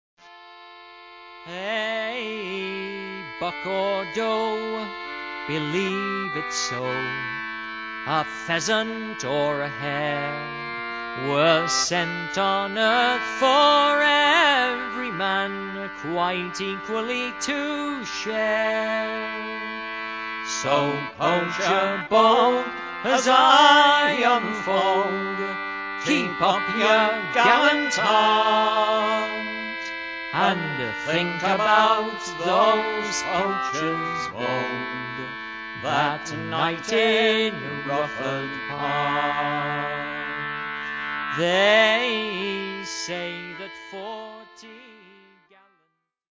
English Folksongs
Recorded and mixed at Soundesign, Brattleboro, VT